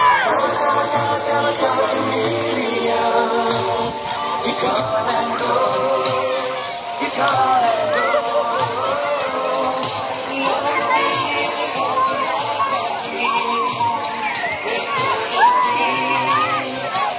Hundreds of local residents jampacked the facility for the first day of the regular swim season Saturday afternoon.
1983-jac-nat-sound-2.wav